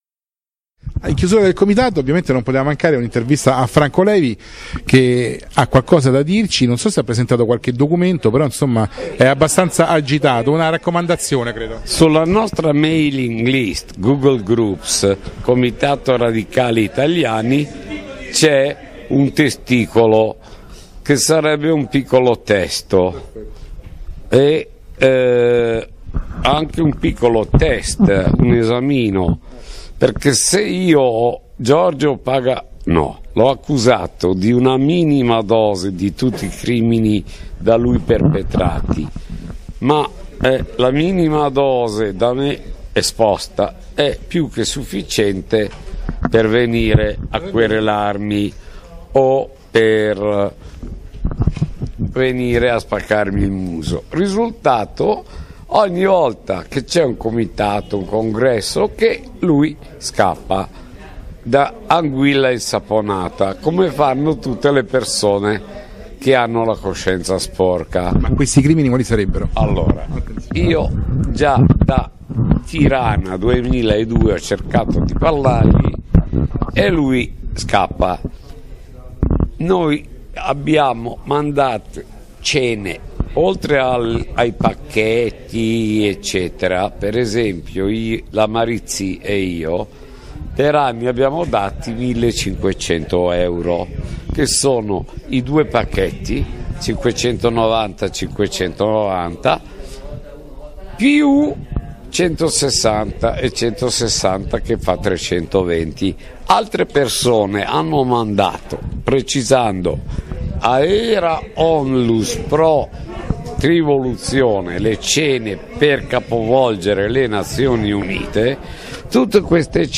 VIDEO | Intervista
Comitato Nazionale Radicali Italiani 11-12-13 aprile 2014, Roma presso la sede del Partito Radicale.